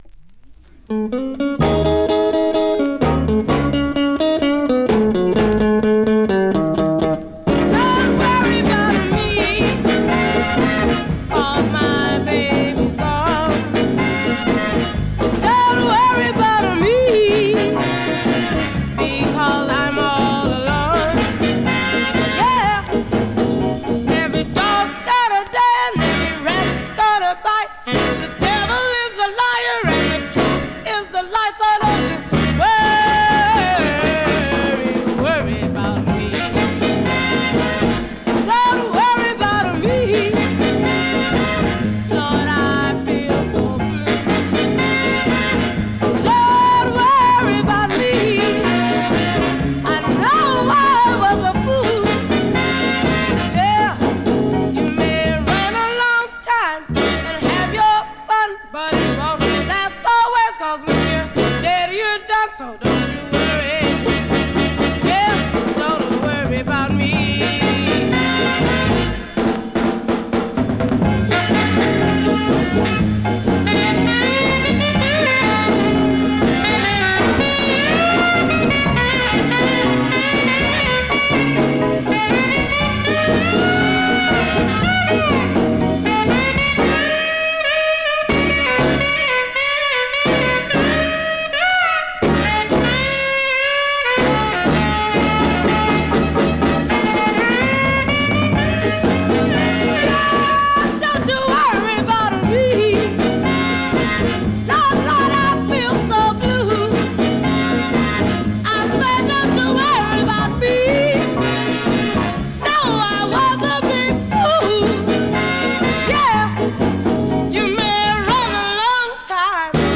Vinyl has a few light marks plays great .
Great mid-tempo Rnb dancer
R&B, MOD, POPCORN